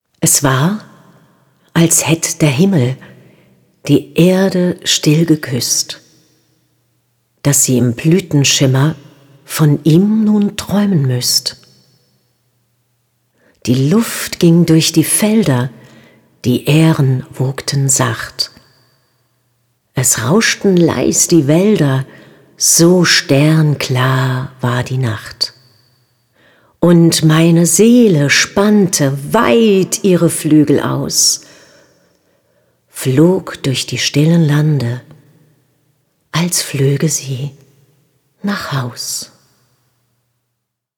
Lesungen, Gedichte und Texte